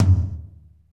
Index of /90_sSampleCDs/Northstar - Drumscapes Roland/DRM_Techno Rock/TOM_F_T Toms x
TOM F T L05R.wav